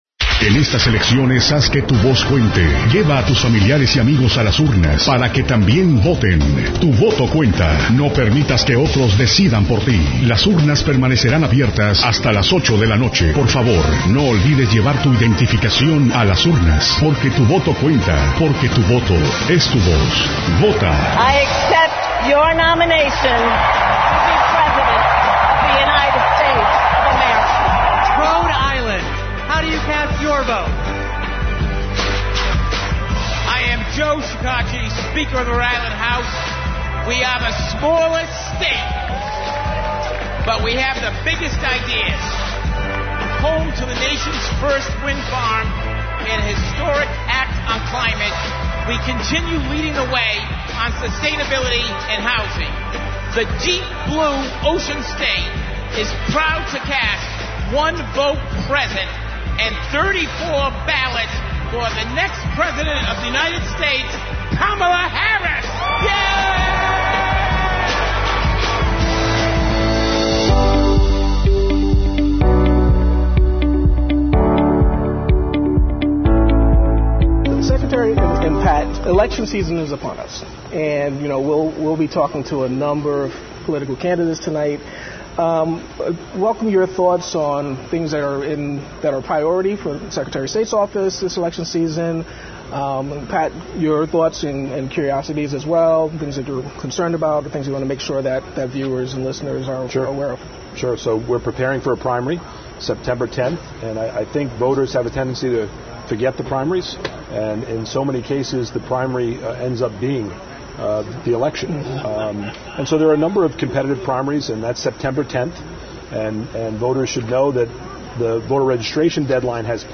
Secretary of State, Gregg M. Amore at LPR’s Political BBQ 2024